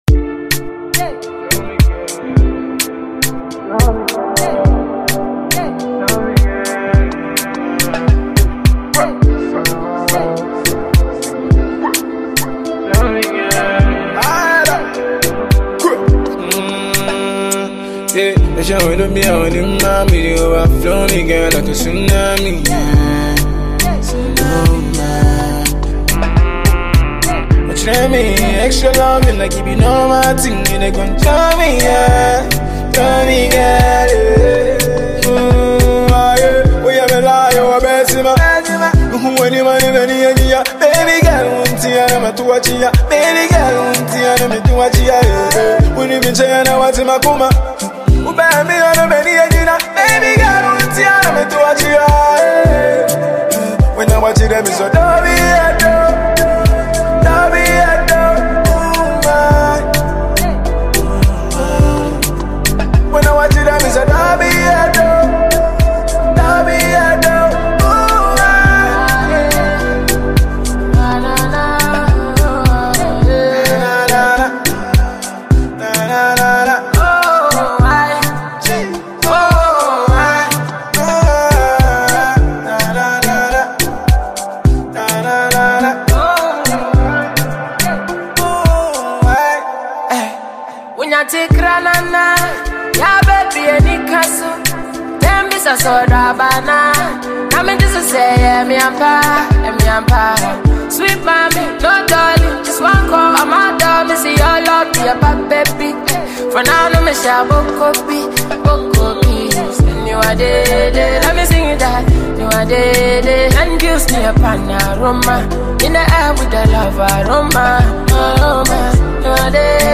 Talented Ghanaian rapper and songwriter